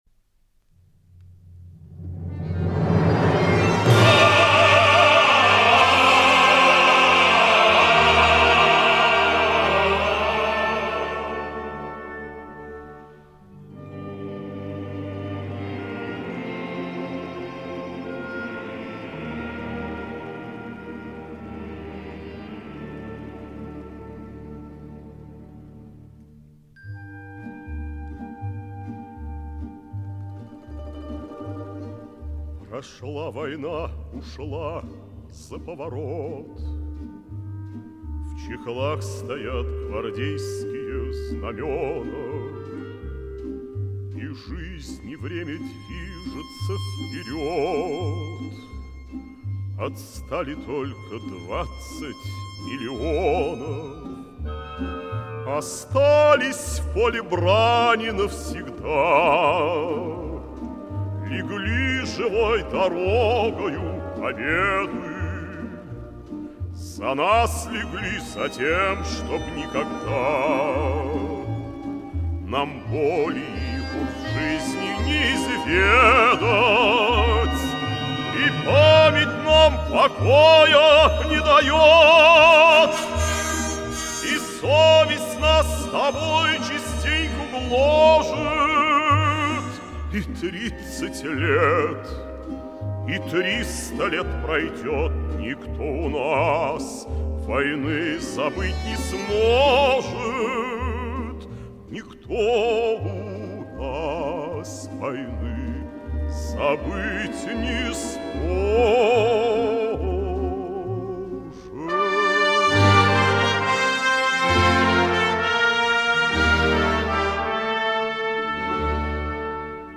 солист